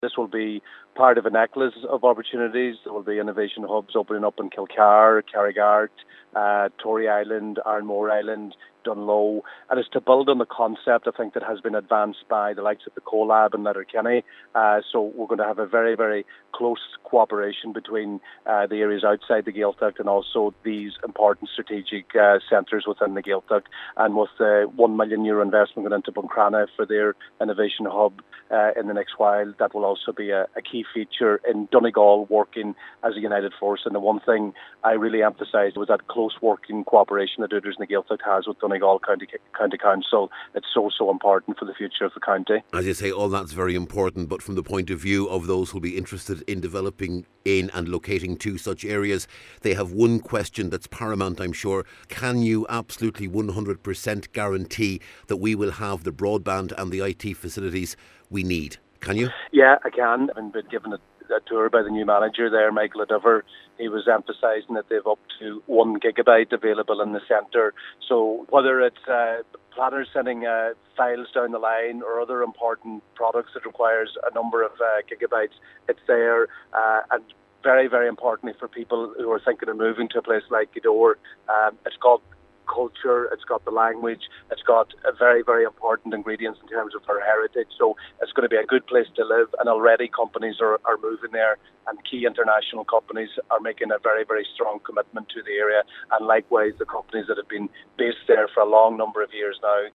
Government Chief Whip, Minister Joe McHugh says this is the first step in developing similar facilities across the county: